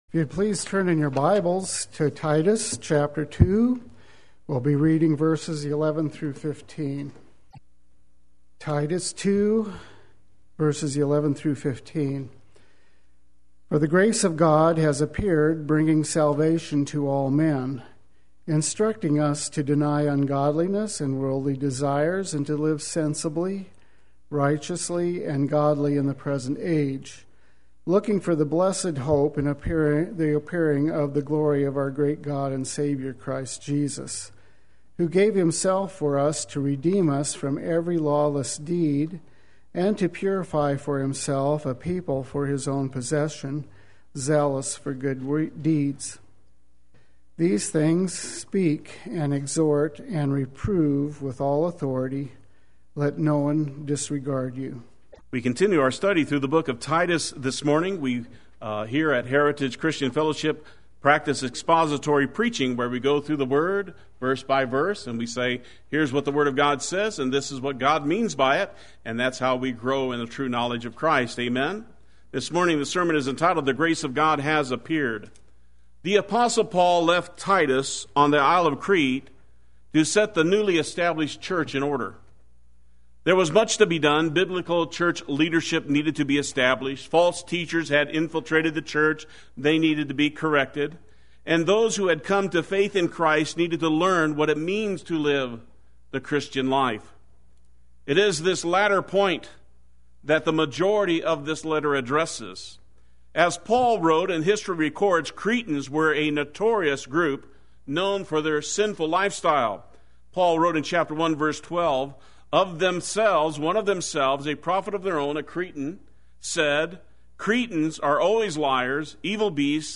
Play Sermon Get HCF Teaching Automatically.
The Grace of God Has Appeared Sunday Worship